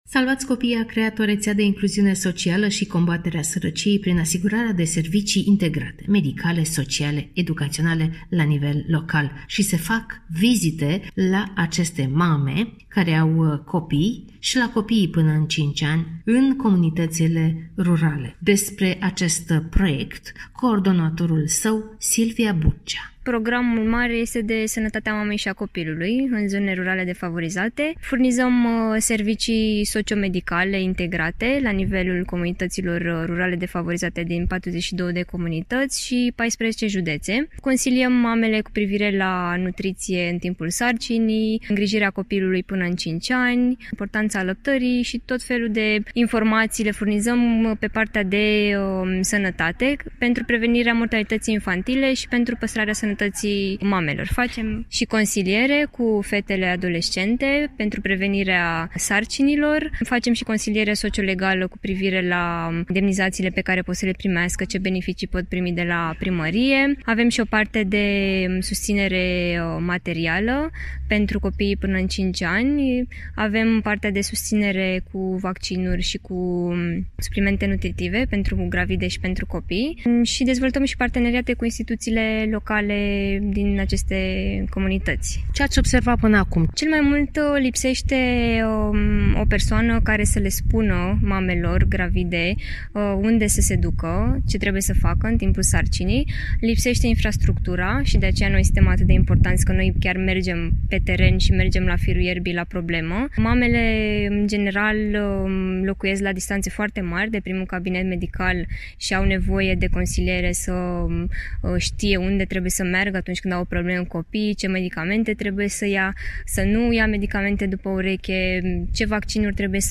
(REPORTAJ) Rețea de incluziune socială și pentru combaterea sărăciei în comunitățile rurale